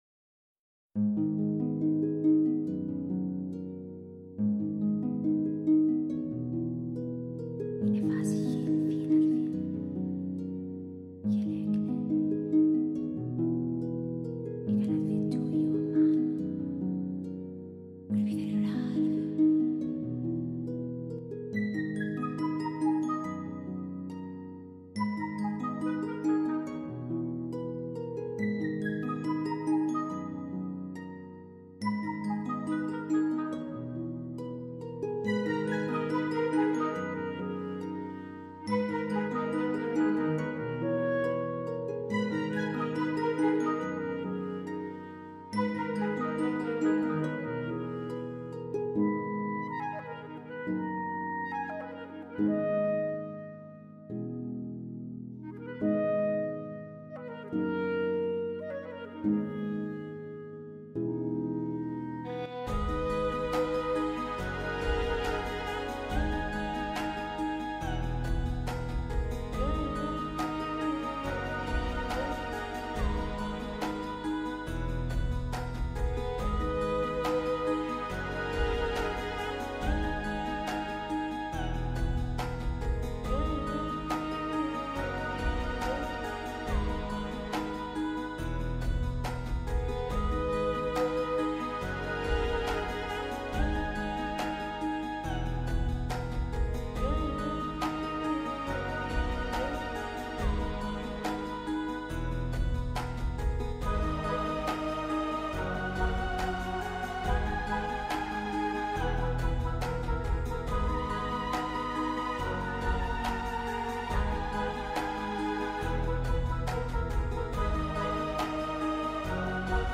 KFgb3hnNGim_Celtic-background.mp3